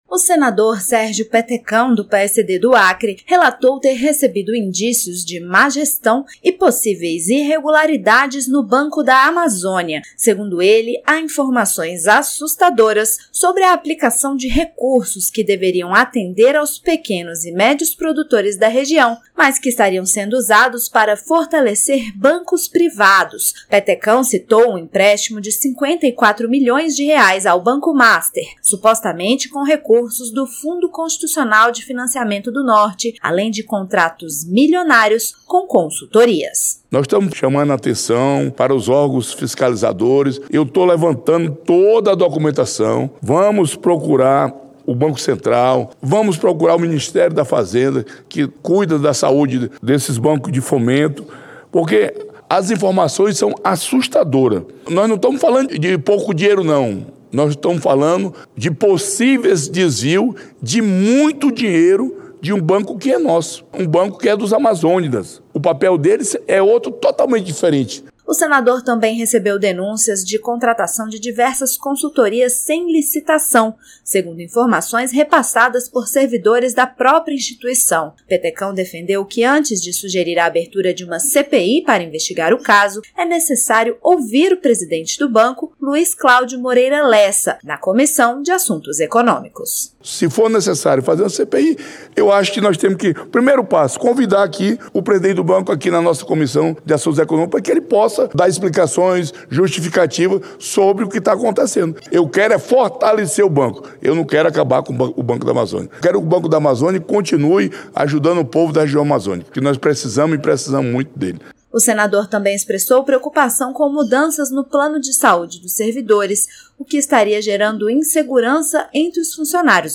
O senador Sérgio Petecão (PSD-AC) fez um alerta, em Plenário, sobre graves indícios de má gestão e possíveis desvios de recursos no Banco da Amazônia. Além do uso de verbas do Fundo Constitucional de Financiamento do Norte (FNO) para bancos privados, o senador revelou na quarta-feira (30) a contratação de consultorias sem licitação e com valores milionários, que chegam a R$ 183 milhões, conforme denúncias feitas por servidores da própria instituição.